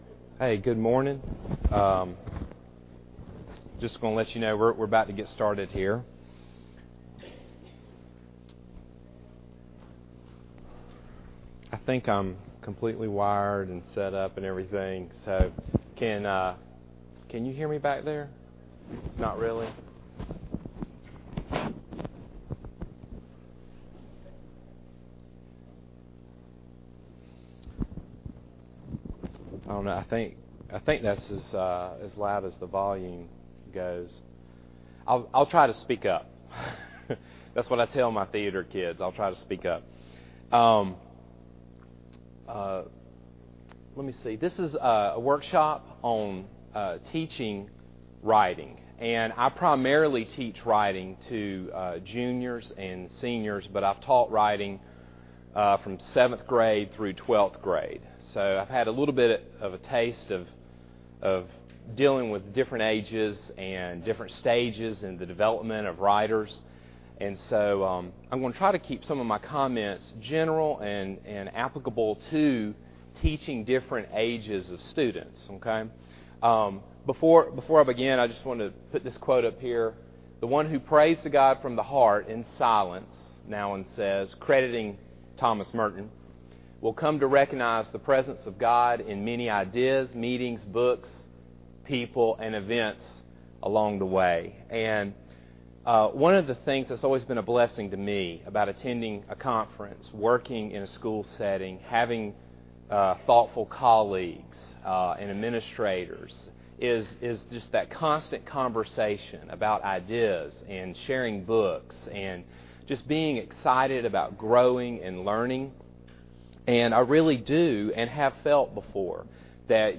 2009 Workshop Talk | 1:01:37 | All Grade Levels, Rhetoric & Composition
The Association of Classical & Christian Schools presents Repairing the Ruins, the ACCS annual conference, copyright ACCS.